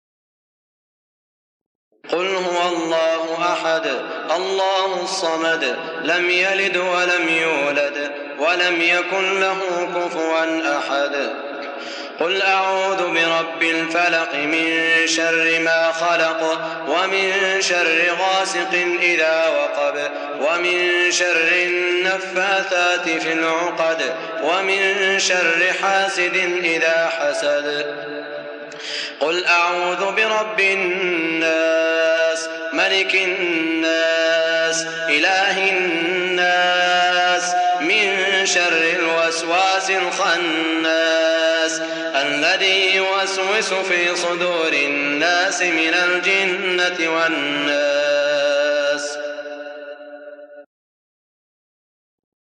سورة ( الإخلاص ، الفلق ، الناس) من عام ١٤٠٨ | بمدينة الرياض > الشيخ سعود الشريم تلاوات ليست من الحرم > تلاوات وجهود أئمة الحرم المكي خارج الحرم > المزيد - تلاوات الحرمين